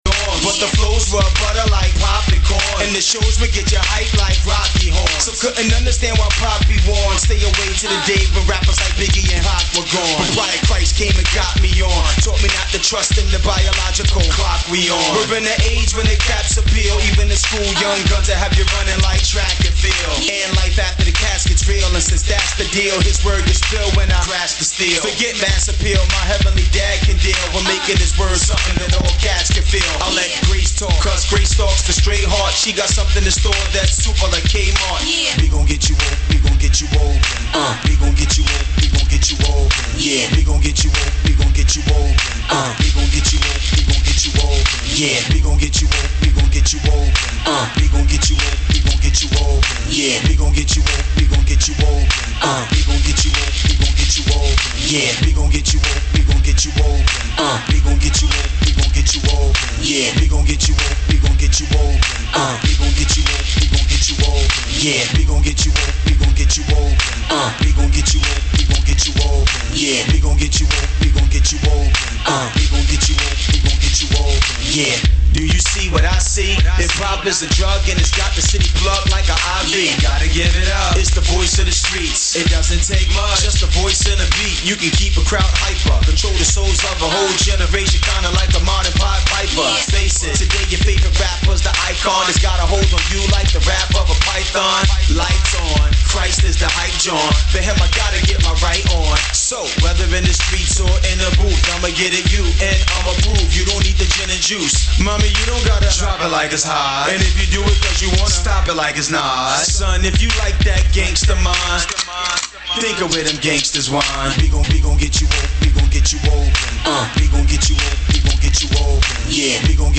Here’s another peek into the Tuesday Night Hype Bible Study presented by Vision Regeneration in Dallas, TX. The question being posed is: What Type of Ground is Your Heart Made Of…? (Mark 4) This is recorded straight to the laptop and unedited; an all inclusive show, without the fixin’ up.